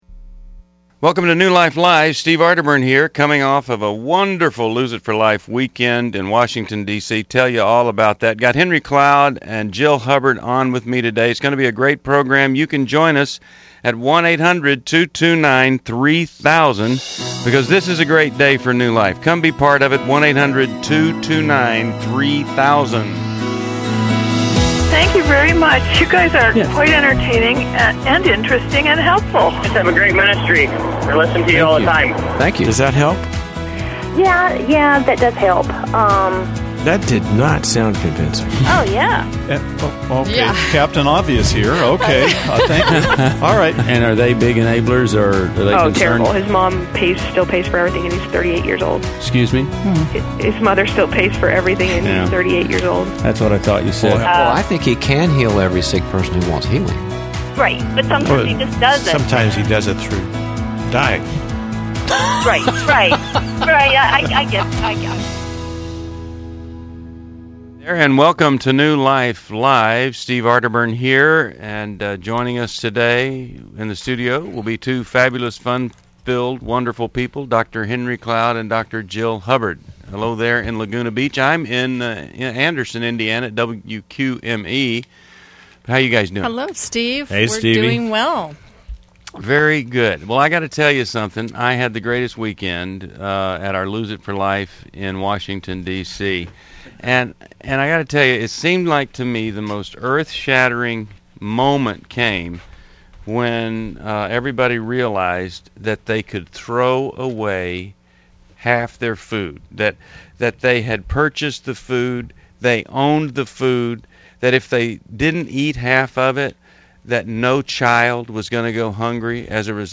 Caller Questions: Am I ready to start dating after being divorced 4 years?